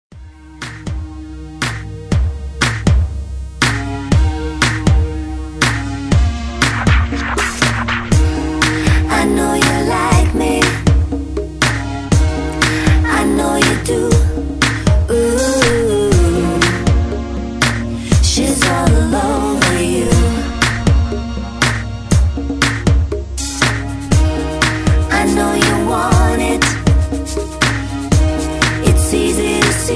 Tags: rap , hip hop , top 40 , beat tracks